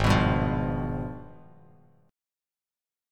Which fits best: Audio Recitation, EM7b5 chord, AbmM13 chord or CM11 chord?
AbmM13 chord